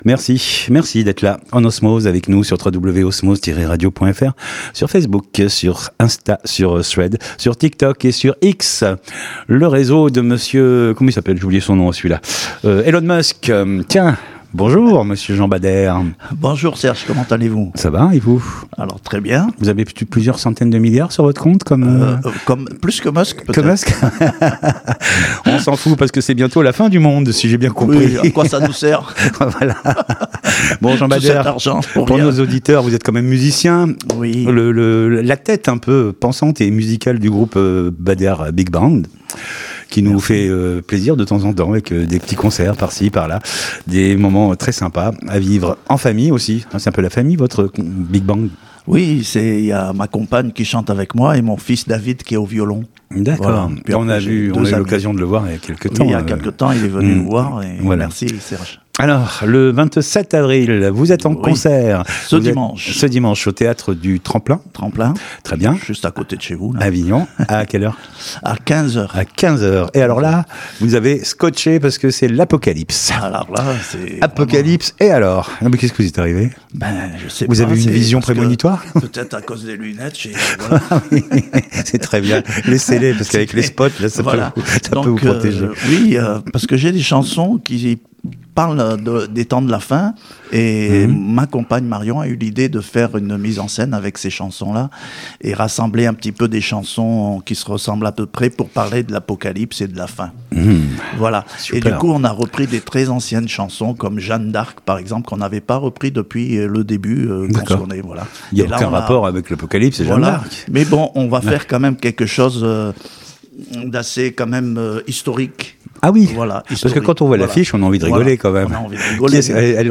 Culture/Loisirs Interviews courtes